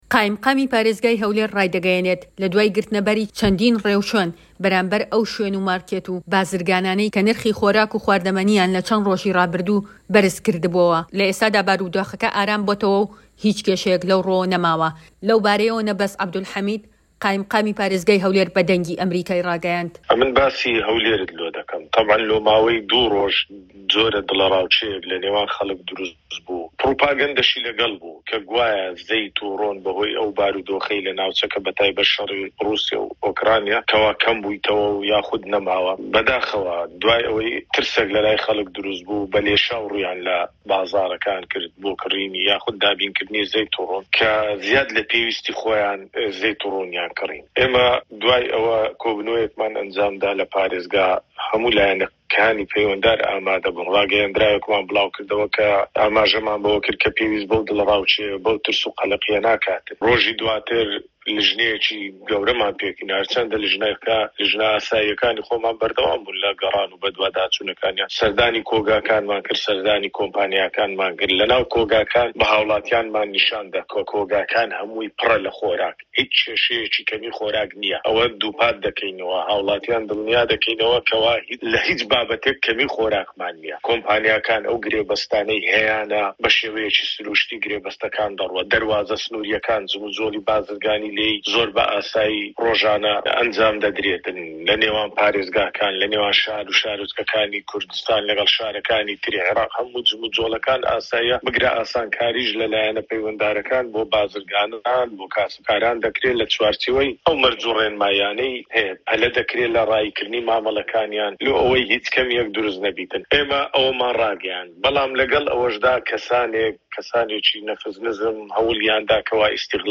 وتووێژ لەگەڵ قائیمقامی هەولێر